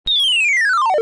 These were all recorded as WAV's and converted to MP3's to save space.